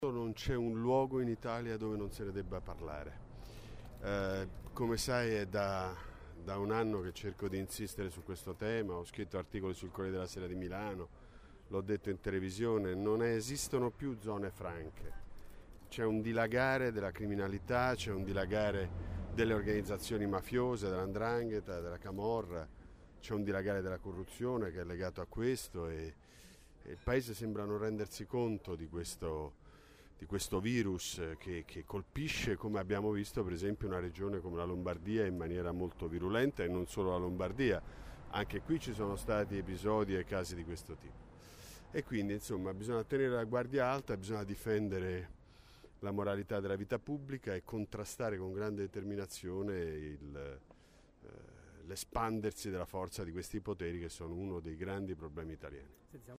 Al cinema Capitol di via Indipendenza ieri sera, martedì,  si è parlato di giustizia, di lotta alla mafia, dei valori sociali che costituiscono una società civile, ma è stata anche campagna elettorale.
Ha chiuso la serata Walter Veltroni, con un invito alla legalità e alla sobrietà della politica.